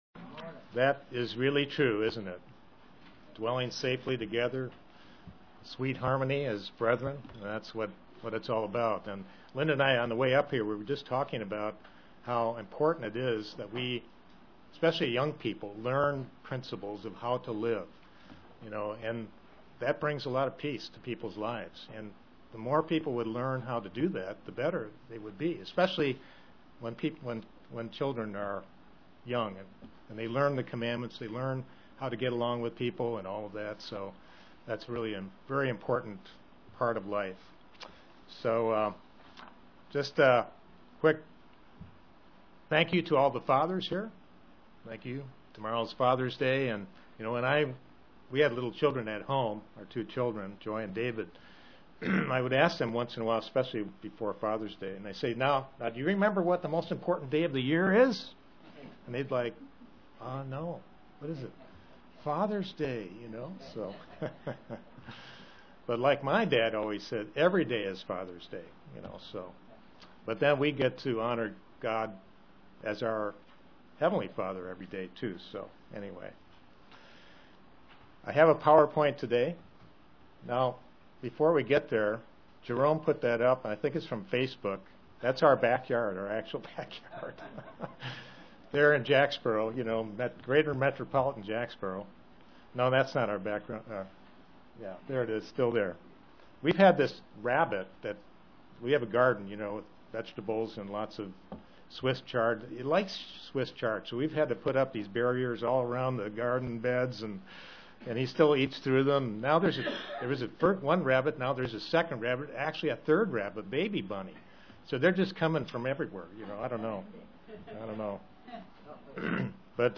Discover what Proverbs 4:23 says in that we should chiefly guard and cherish our hearts. (Presented to the London KY, Church)